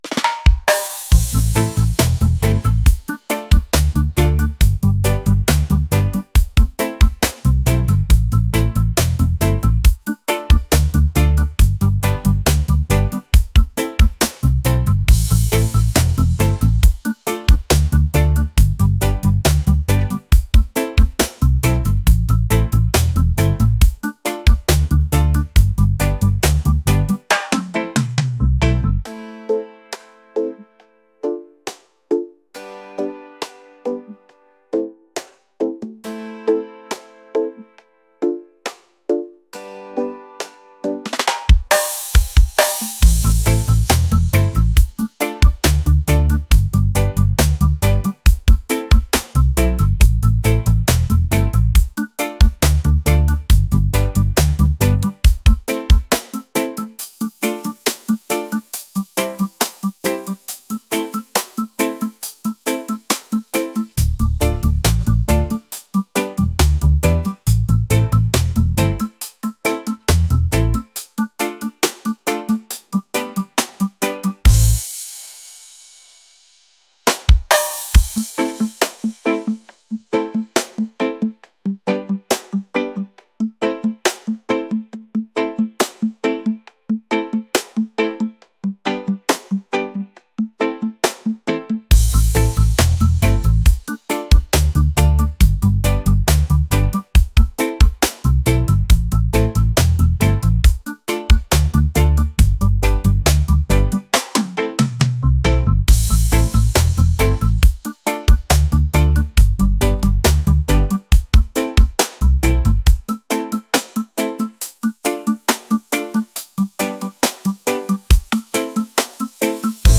reggae | groovy | upbeat